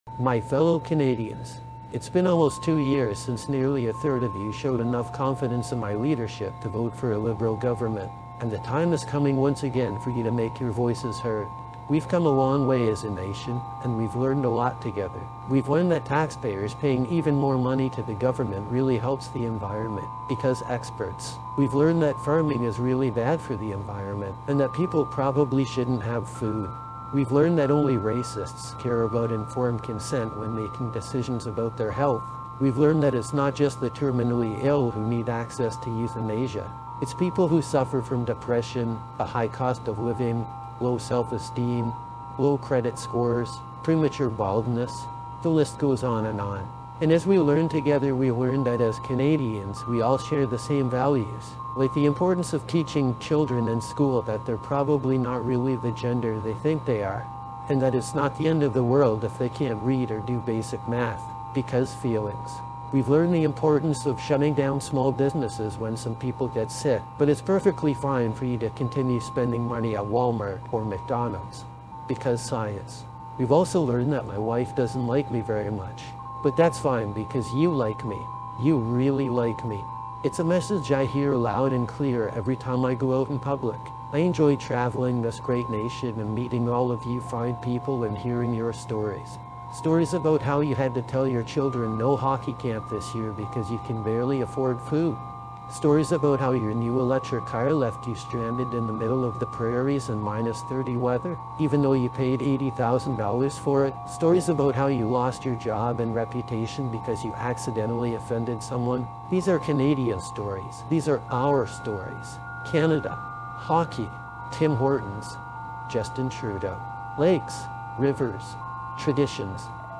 I Asked AI To Make a Justin Trudeau Campaign Commercial